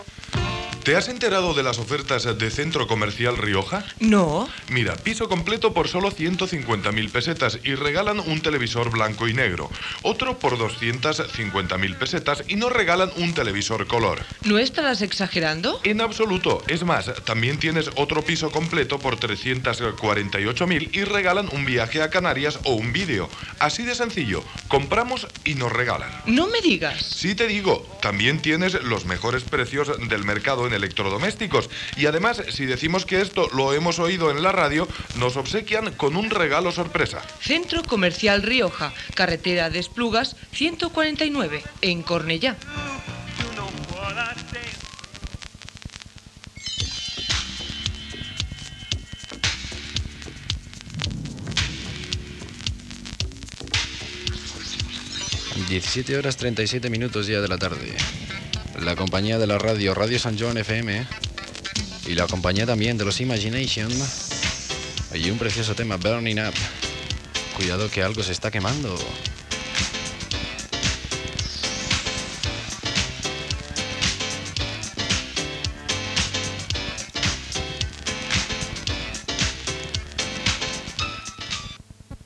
1b7dbaa630306fdd19dcfd0b8d1ed5c6b6cea07b.mp3 Títol Ràdio Sant Joan FM Emissora Ràdio Sant Joan FM Titularitat Tercer sector Tercer Sector Comercial Descripció Publicitat, hora, identificació i tema musical.